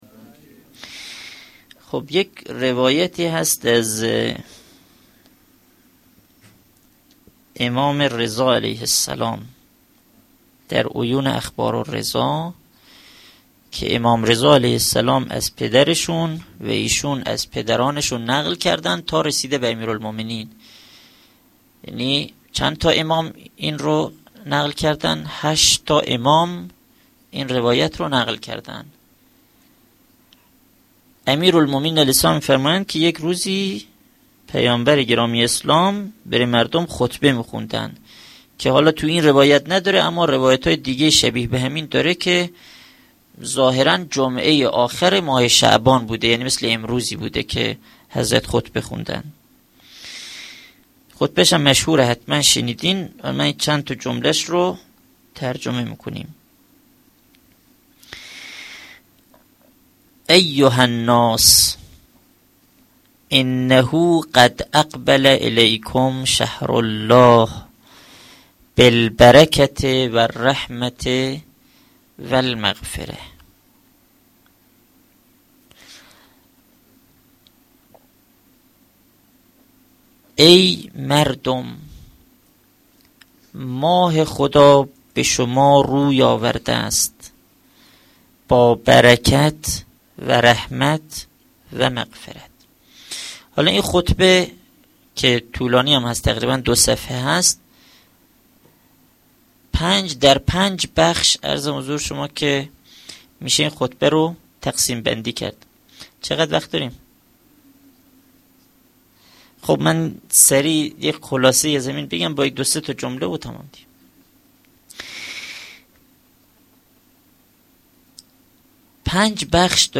سخنرانی
sokhanrani-Rozatol-abbas.Esteghbal-az-Ramezan.mp3